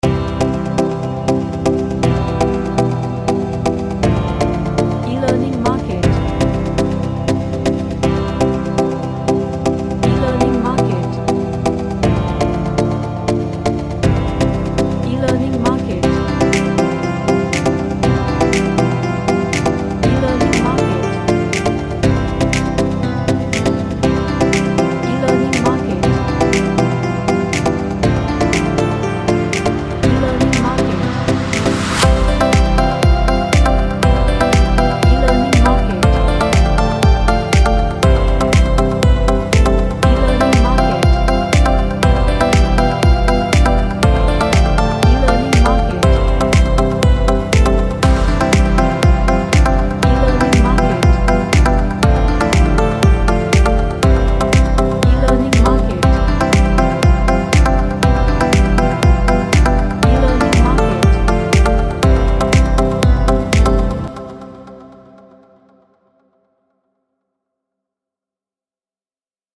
A tropical vibe song with plucks in it.
Happy